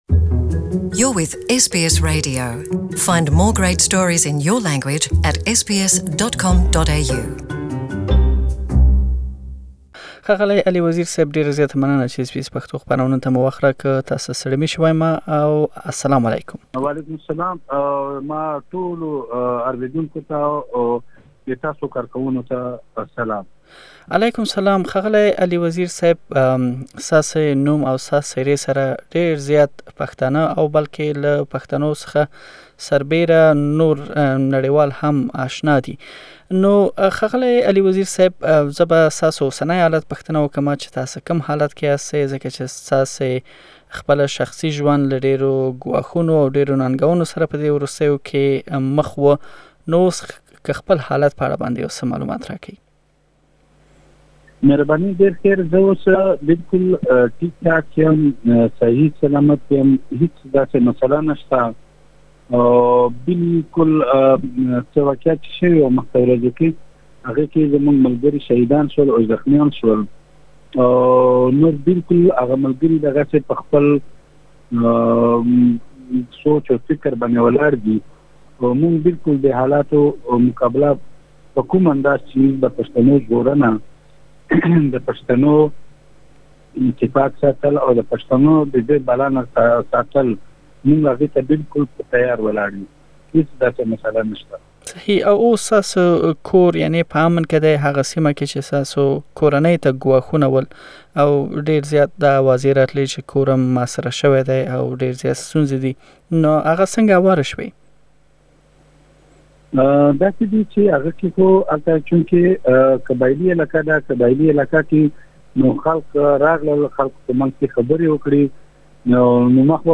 Exclusive Interview with Ali Wazir